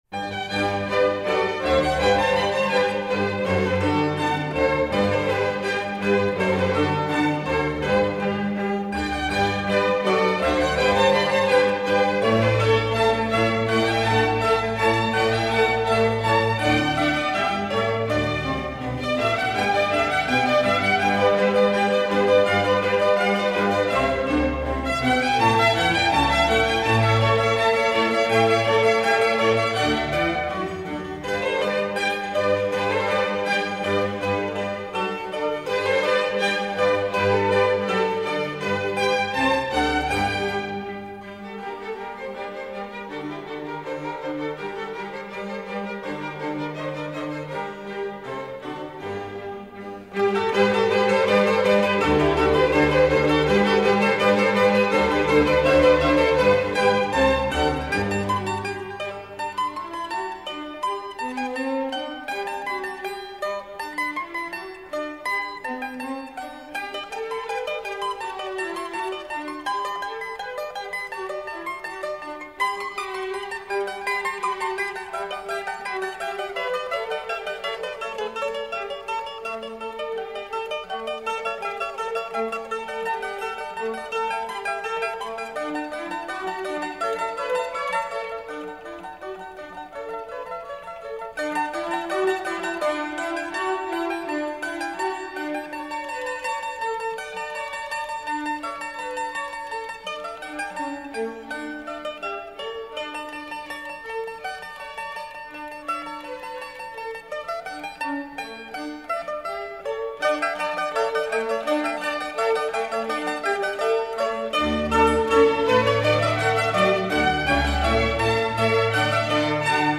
Allegro.